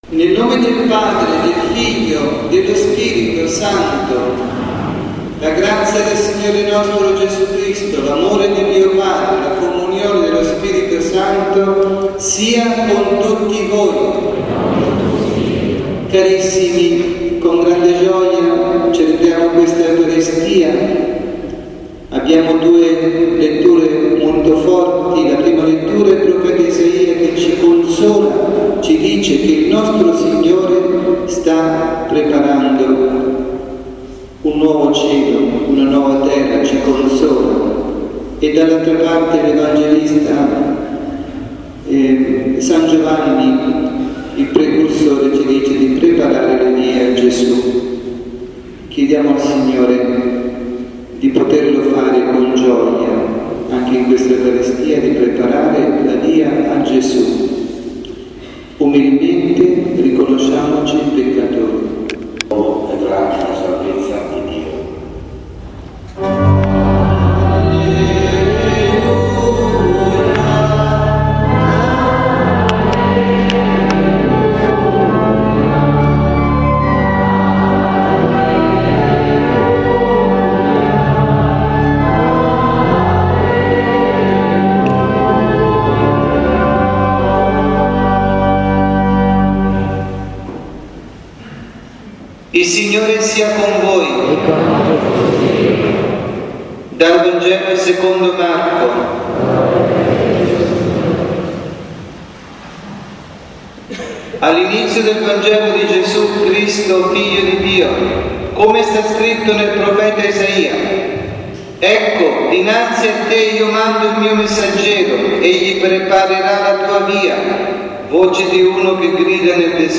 Omelia